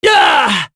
Mitra-Vox_Damage_03.wav